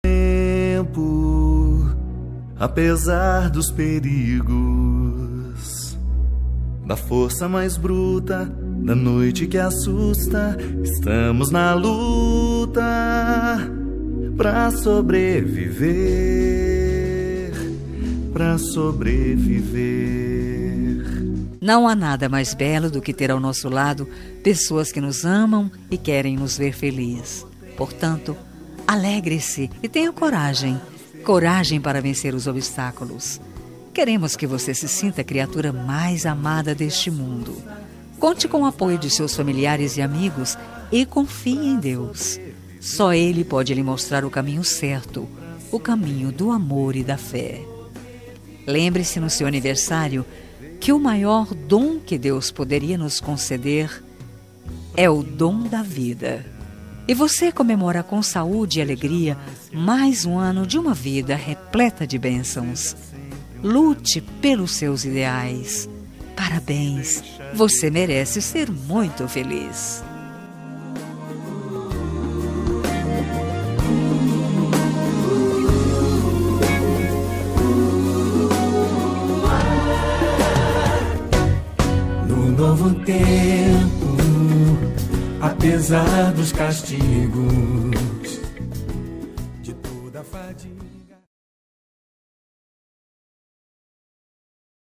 Aniversário Religioso – Voz Feminina – Cód: 34821